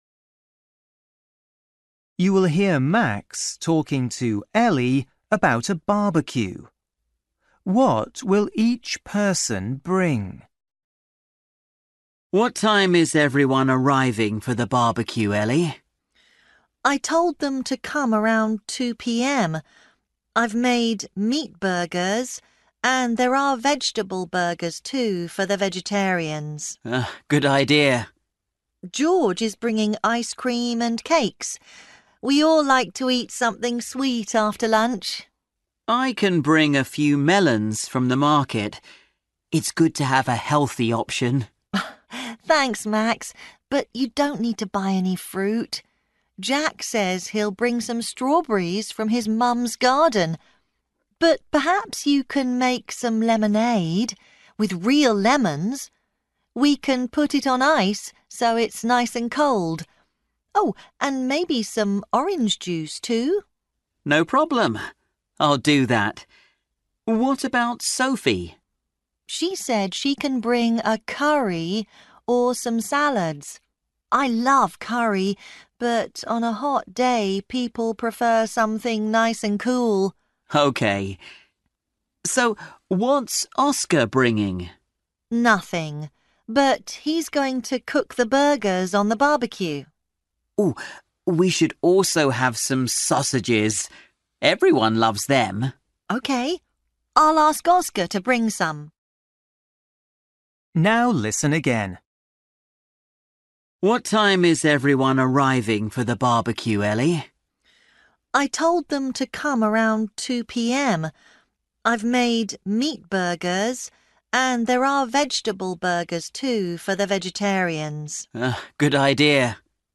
You will hear Max talking to Ellie about a barbecue.